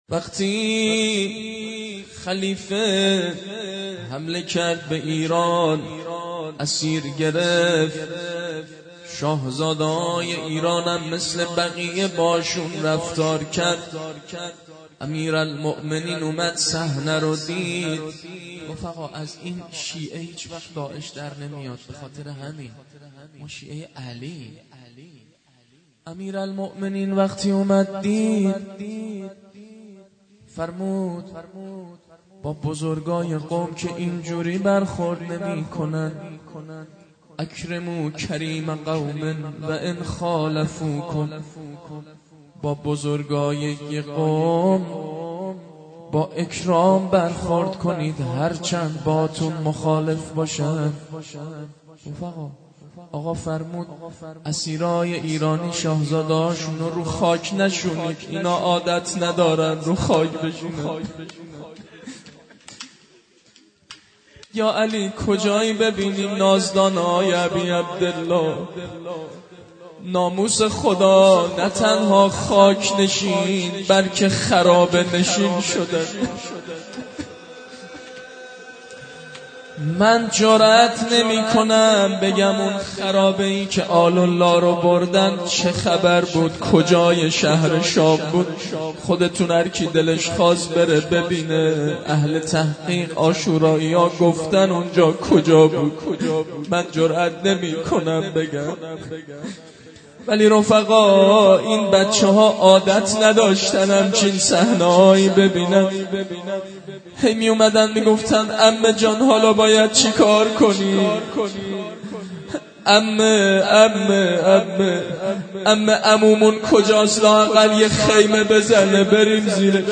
فایل های صوتی عزاداری شهادت حضرت رقیه(س) ۱۳۹۳ - هیأت روضة الزهراء دزفول
سخنرانی